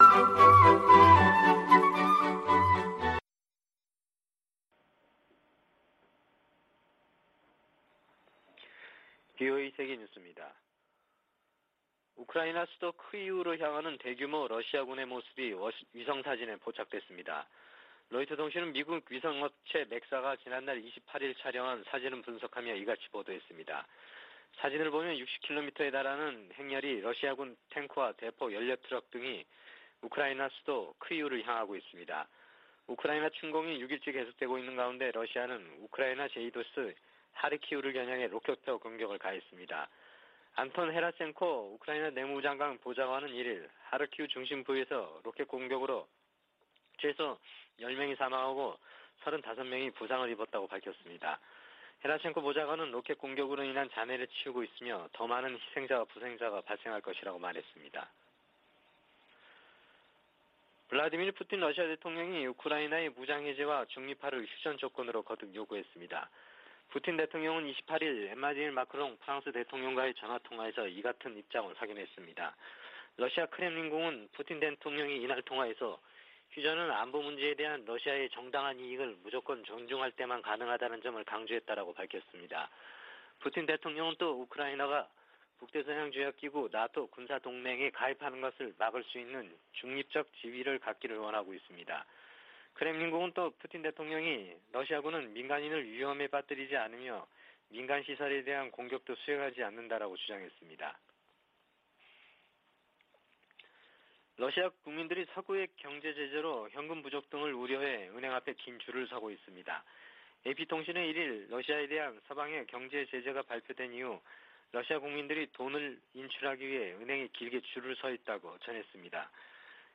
VOA 한국어 아침 뉴스 프로그램 '워싱턴 뉴스 광장' 2022년 3월 2일 방송입니다. 미국, 영국, 일본 등 유엔 안보리 11개국이 북한 탄도미사일 발사를 규탄하고 단호한 제재 이행 방침을 확인했습니다. 괌 당국은 북한의 탄도미사일 시험 발사 재개와 관련해 모든 움직임을 계속 감시하고 있다고 밝혔습니다. 미국의 전문가들은 북한의 최근 미사일 발사가 우주개발을 가장한 탄도미사일 시험일 뿐이라고 지적하고 있습니다.